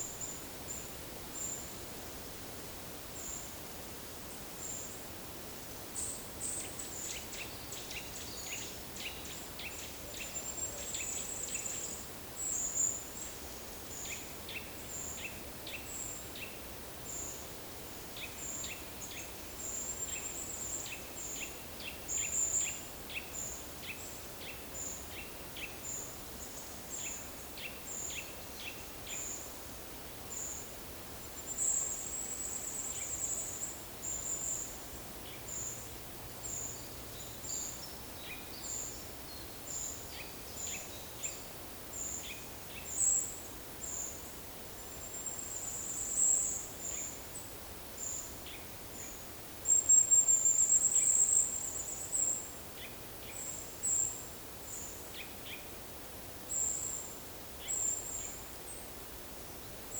Monitor PAM
Certhia familiaris
Sitta europaea
Regulus ignicapilla
Certhia brachydactyla